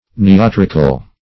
Search Result for " neoterical" : The Collaborative International Dictionary of English v.0.48: Neoteric \Ne`o*ter"ic\, Neoterical \Ne`o*ter"ic*al\, a. [L. neotericus, Gr. newteriko`s, fr. new`teros, compar. of ne`os young, new.]